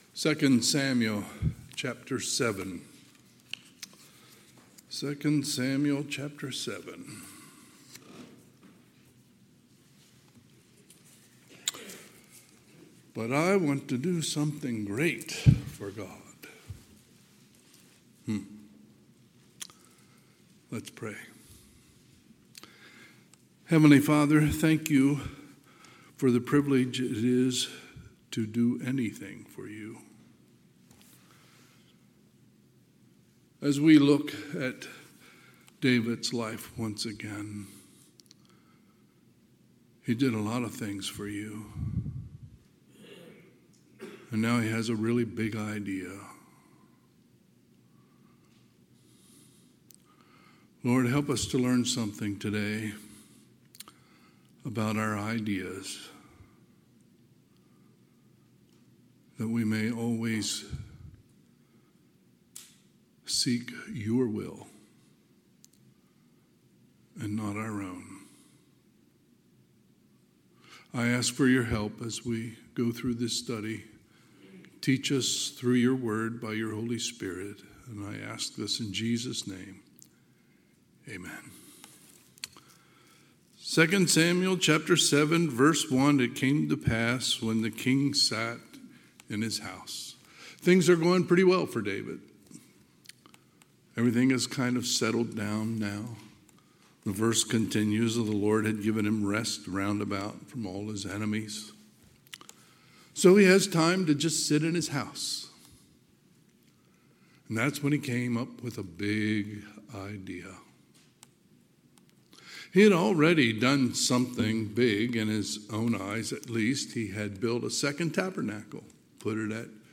Sunday, July 20, 2025 – Sunday AM